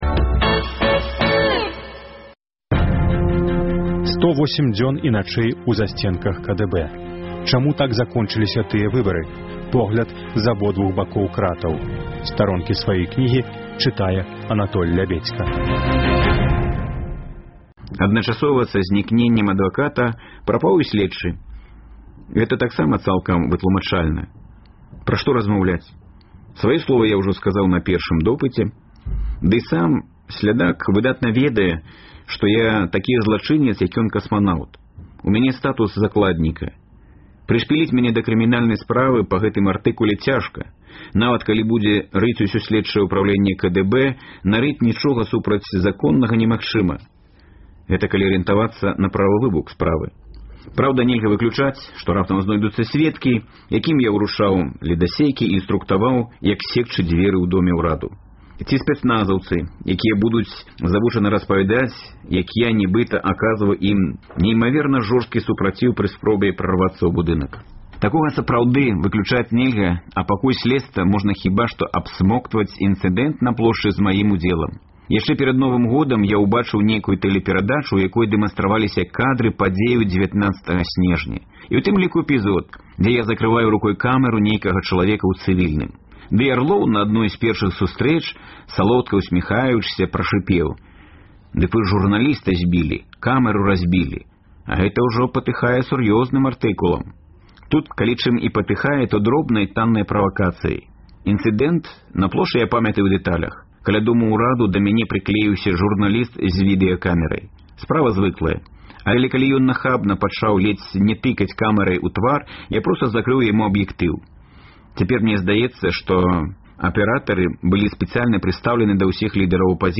На хвалях Радыё Свабода гучаць разьдзелы кнігі Анатоля Лябедзькі «108 дзён і начэй у засьценках КДБ» у аўтарскім чытаньні.